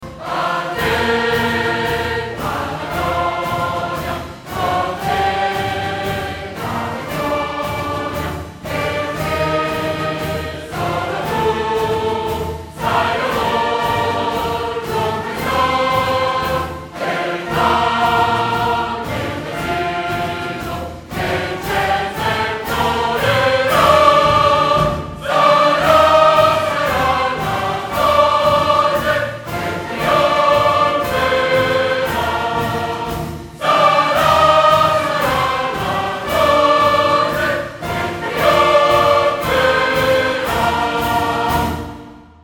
suoneria per smartphone